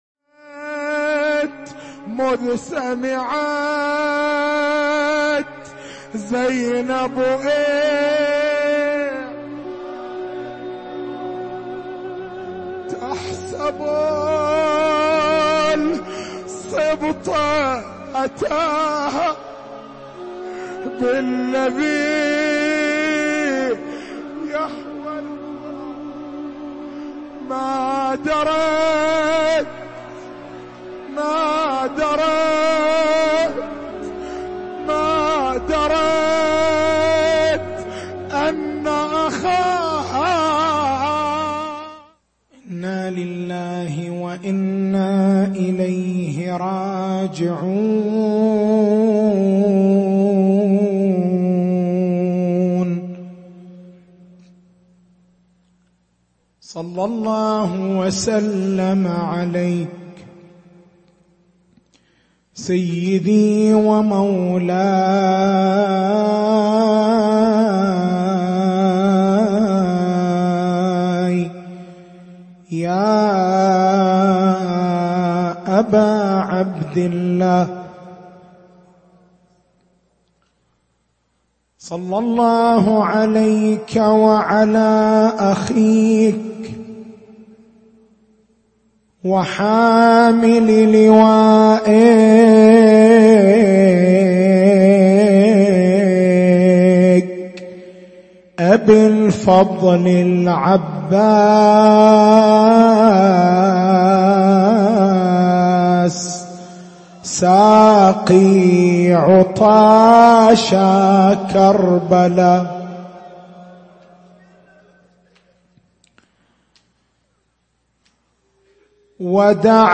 تاريخ المحاضرة
حسينية الزين بالقديح